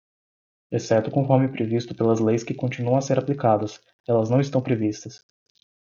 /pɾeˈvis.tu/